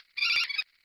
sounds / monsters / rat / attack_5.ogg
attack_5.ogg